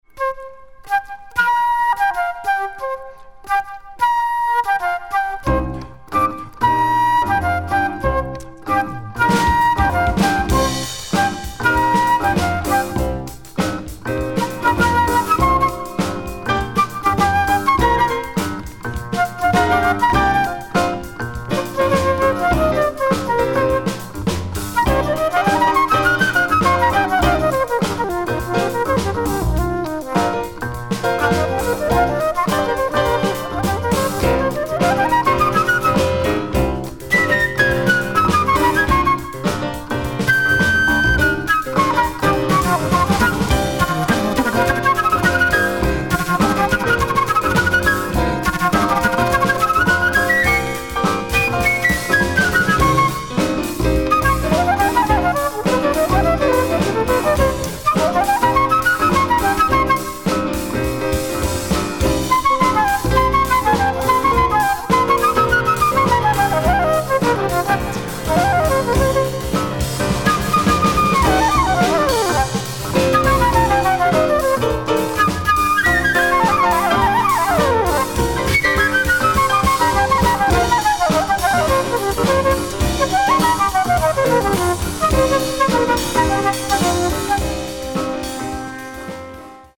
Subtle mix of free jazz and spiritual afro jazz groove.
sax, flute and kalimba
bass
piano